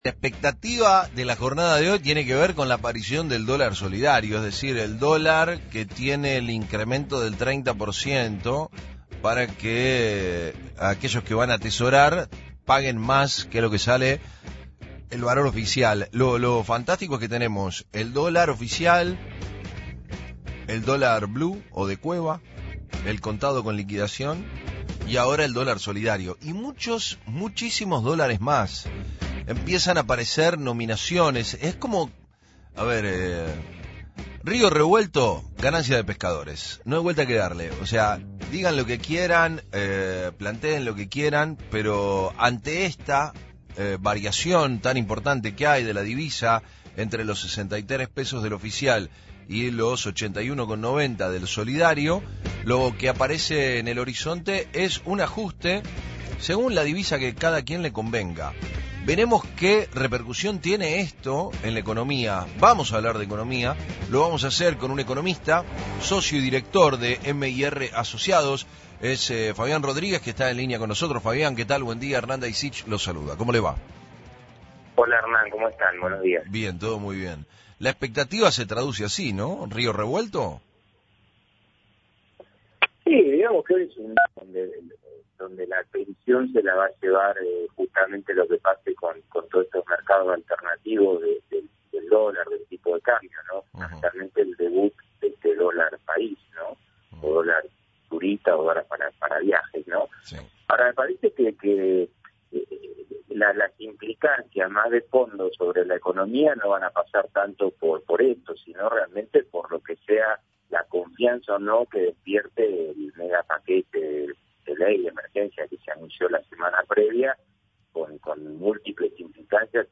Sin embargo, el especialista explicó, en diálogo con FRECUENCIA ZERO , que el cepo tiende a cuidar las divisas para el comercio exterior (exportaciones e importaciones) y el pago de la deuda, lo que generó mayor confianza en bonos y mercados.